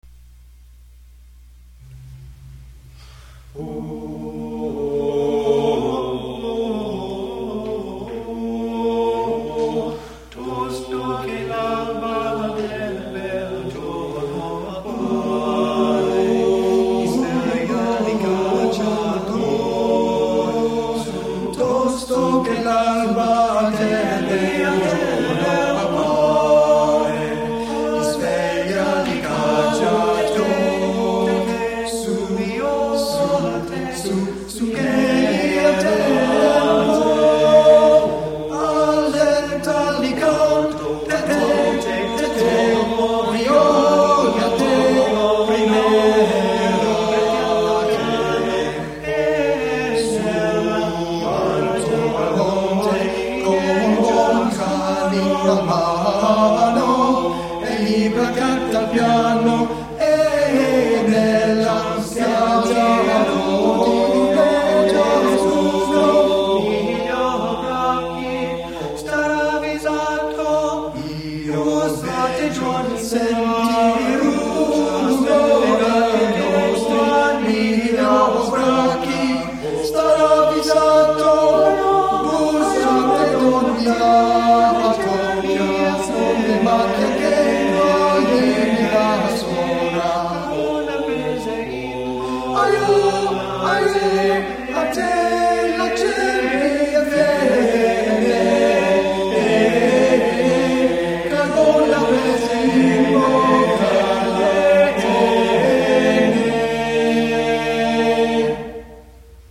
Choir Performances
Performed during a concert in Rohnert Park